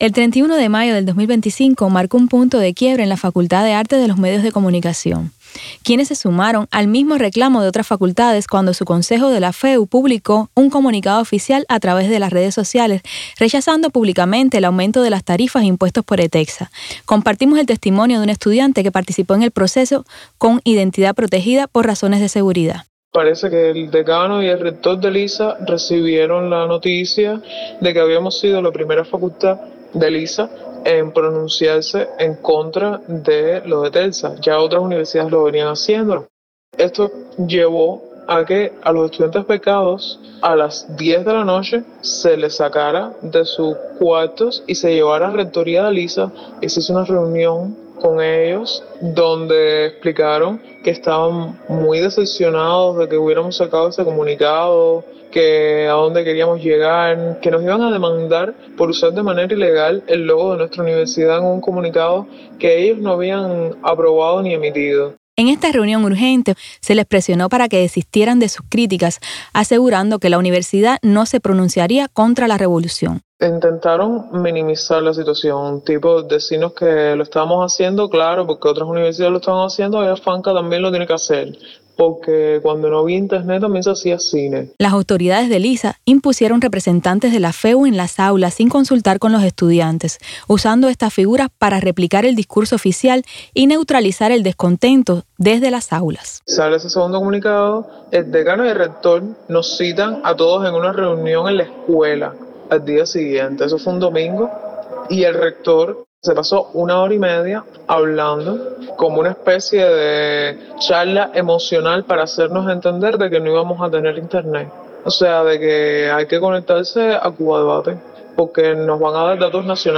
Testimonio de un estudiante del ISA sobre protesta contra ETECSA